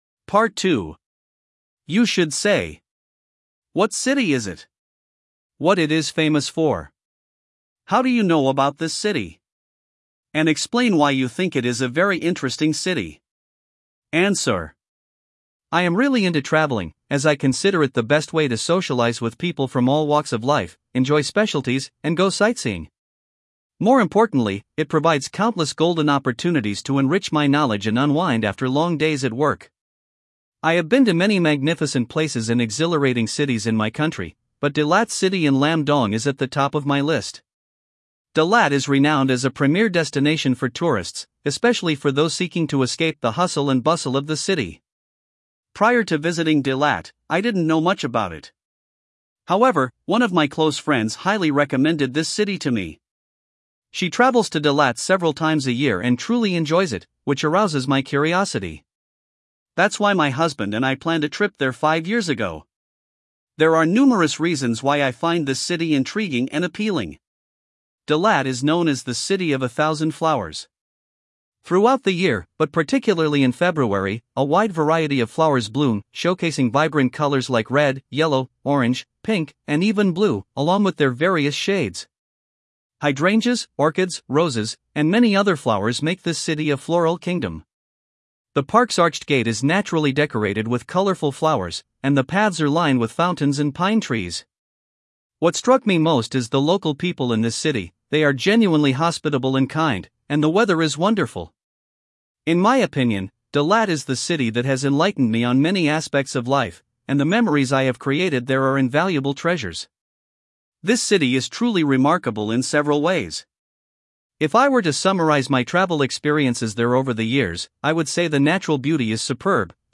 Guy (English US)